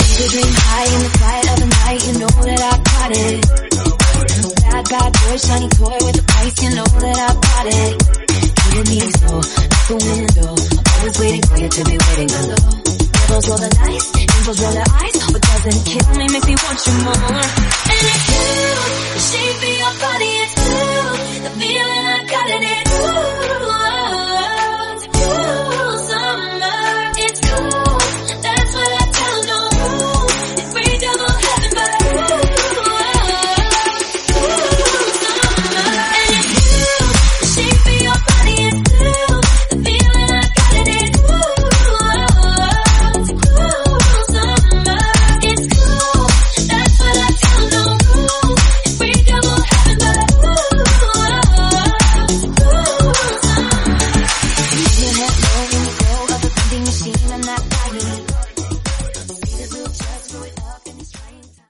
Genre: BOOTLEG
Clean BPM: 124 Time